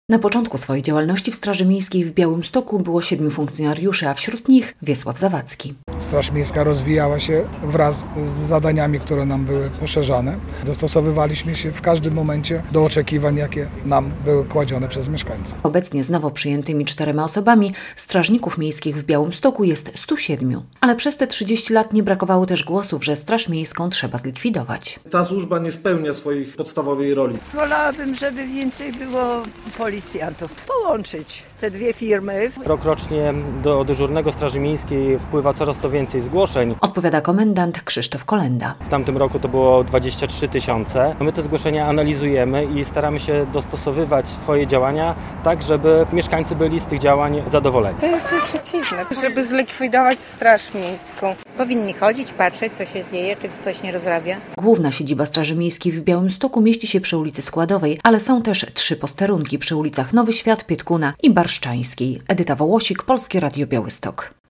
Straż miejska w Białymstoku świętuje 30-lecie istnienia - relacja